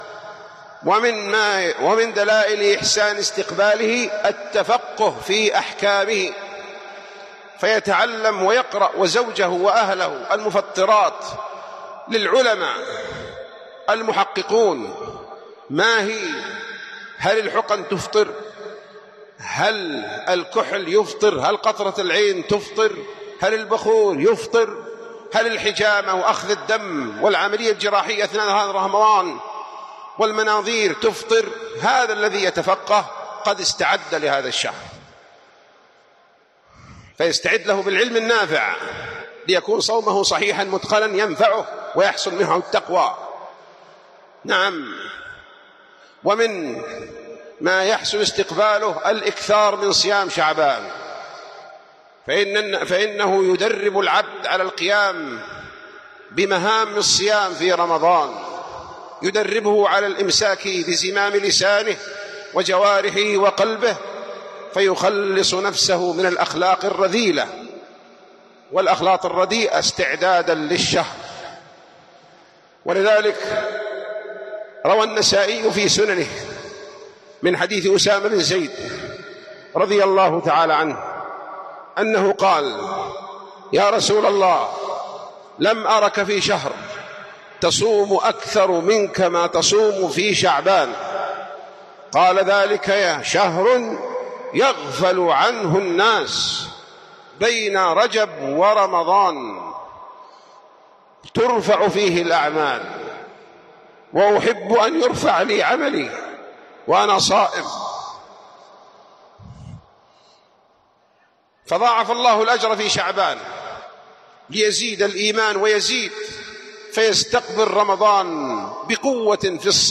674 [ درر قحطانية ] - كيف تحسن إستقبال رمضان { خطبة الجمعة} .